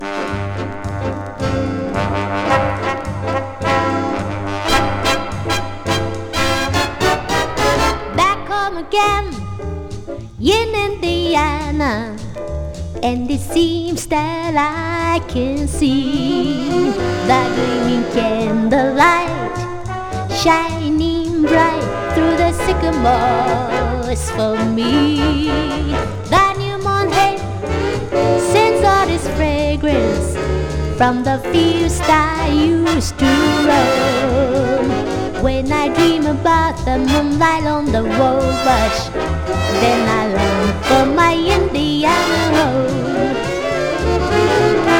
Jazz, Vocal　USA　12inchレコード　33rpm　Stereo